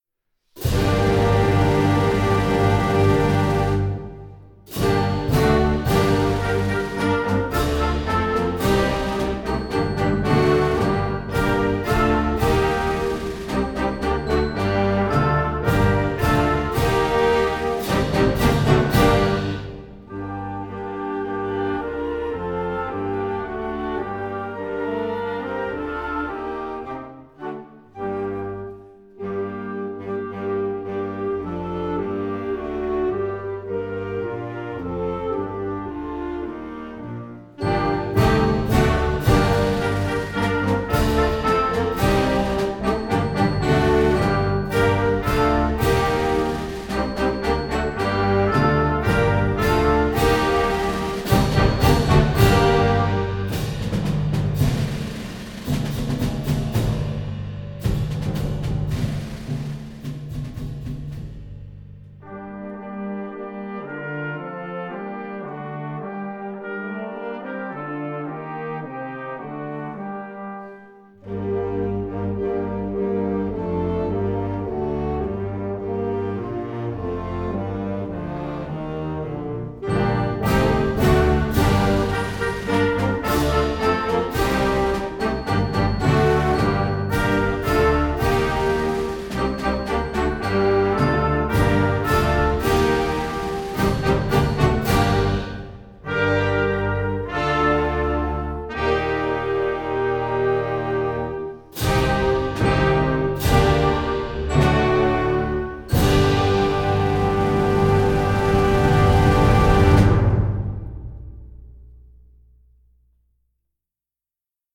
Gattung: Konzertwerk für Jugendblasorchester
Besetzung: Blasorchester